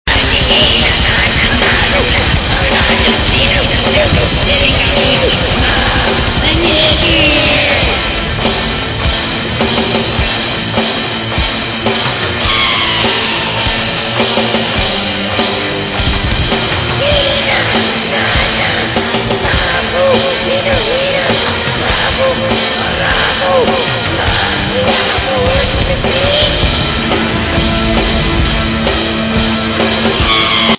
Production: Varied but mostly cavernous with presence.